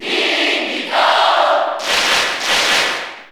File:Diddy Kong Cheer Italian SSB4 SSBU.ogg
Diddy_Kong_Cheer_Italian_SSB4_SSBU.ogg